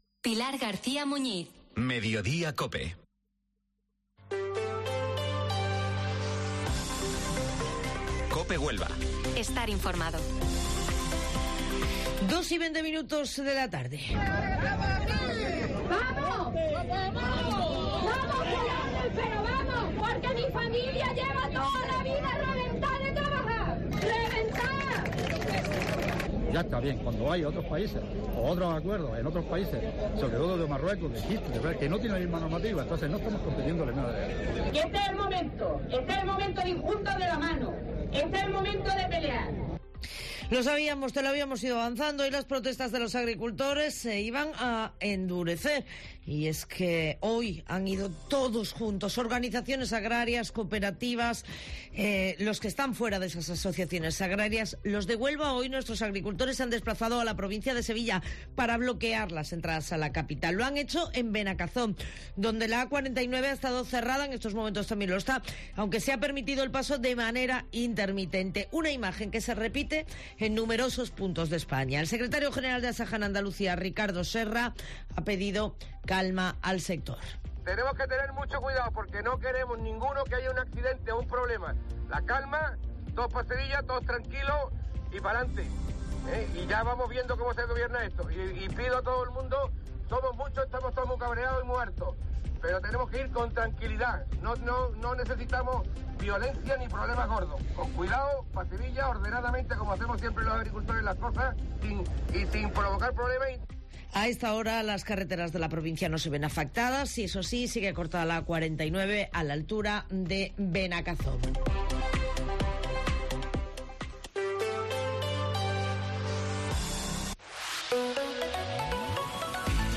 AUDIO: Escuchas las noticias del día en Huelva y su provincia